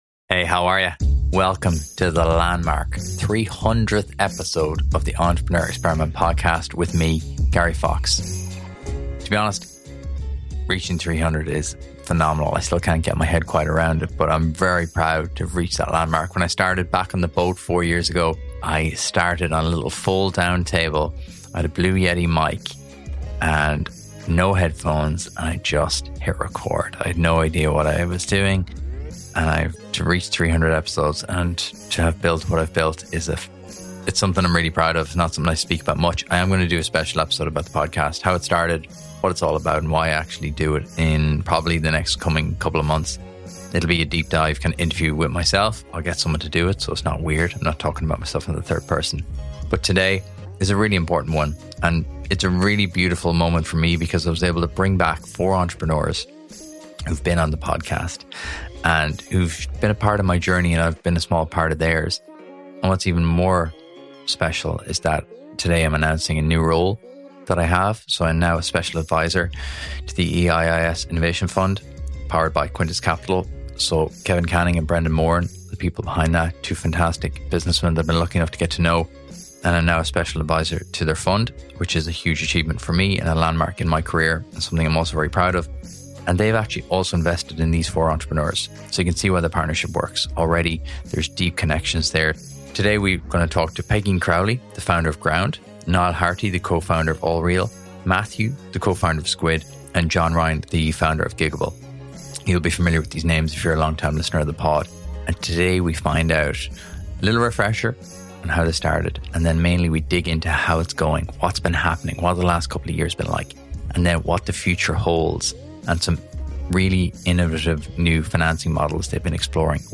To celebrate a landmark episode, I invited back 4 of the best entrepreneurs I've had the pleasure of meeting over the last 300 episodes for a special live episode.